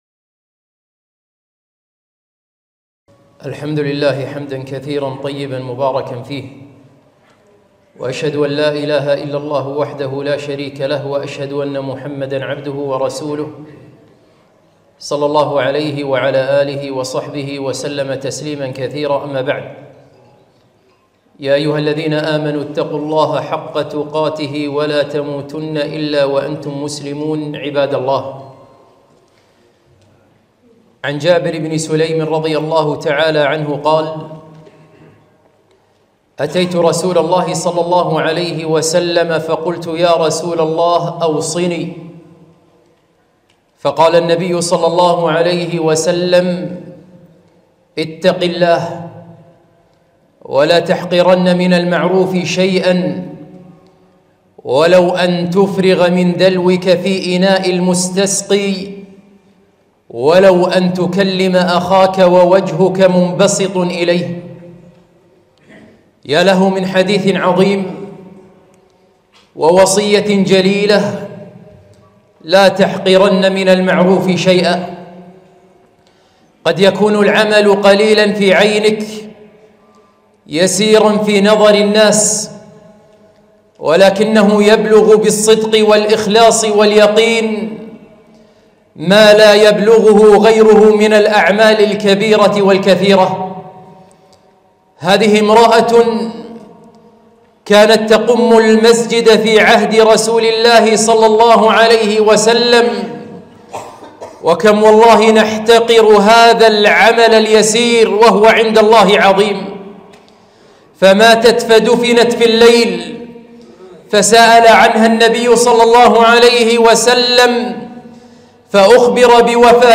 خطبة - لا تحقرن من المعروف شيئا